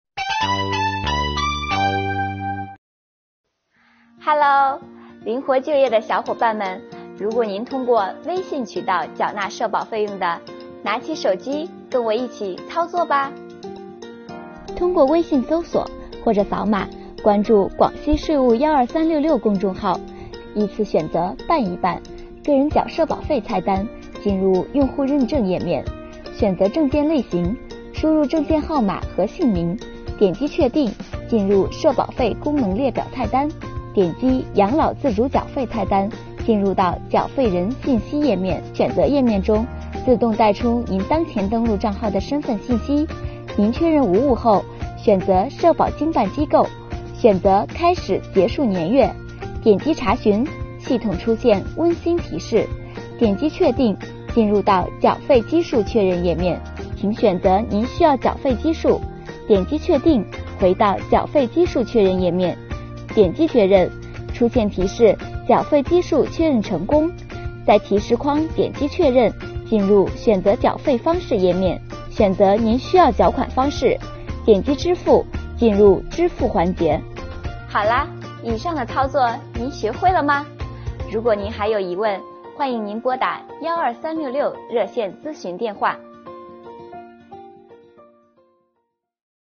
灵活就业人员的小伙伴们，现在缴社保费就请关注广西税务12366微信公众号，打开下面的视频，跟着税务小姐姐一起来轻松缴社保吧！